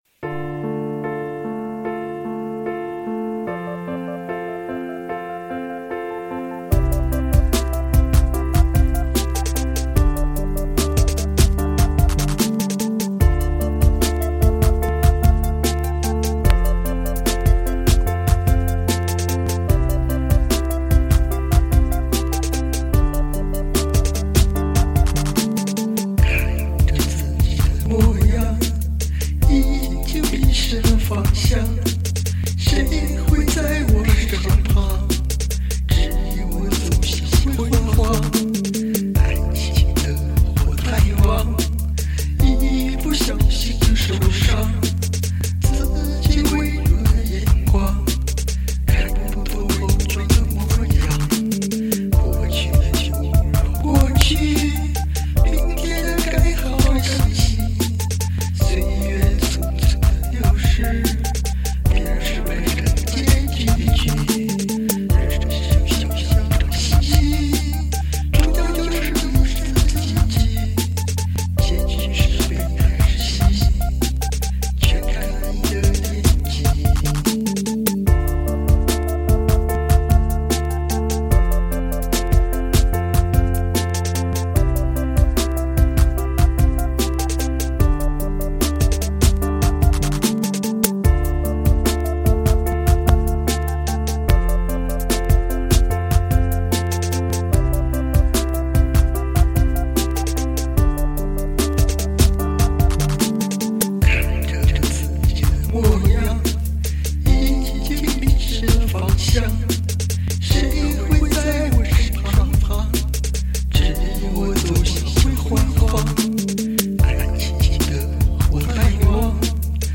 曲风：流行